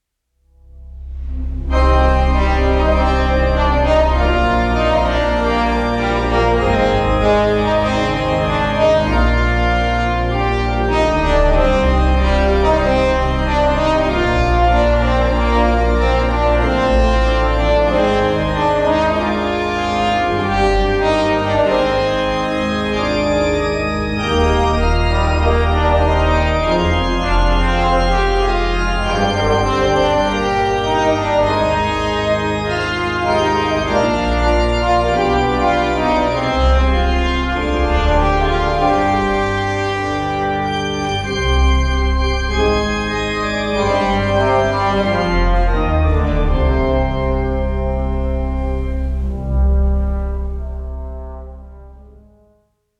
FOR TROMBONE AND ORGAN
4′           tenor-trombone, organ